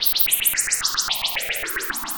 Index of /musicradar/rhythmic-inspiration-samples/110bpm
RI_RhythNoise_110-03.wav